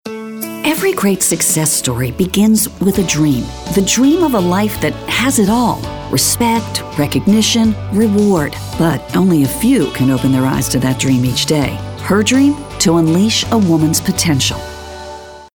anti-announcer, caring, compelling, conversational, friendly, inspirational, mature, motivational, thoughtful, warm